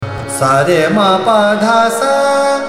Raga
Raga Durga evokes a sense of devotion, serenity, and tranquility.
ArohaS R m P D S’
Durga (Aroha)